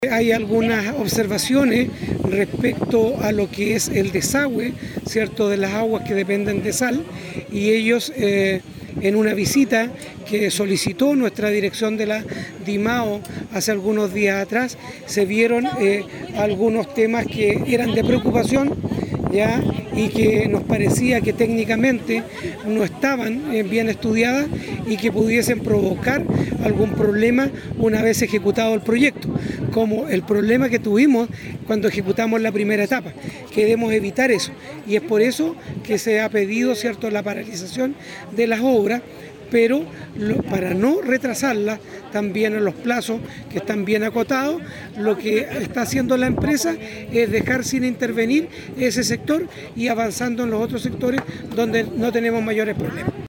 La autoridad también dio cuenta que en el caso del proyecto de remodelación de la Costanera en su segunda etapa, se está avanzando en el comienzo de las labores, con la excepción del tramo que está paralizado por el resguardo de tuberías que pertenecen a Essal.